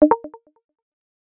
alert_asterisk_10 Sound
alert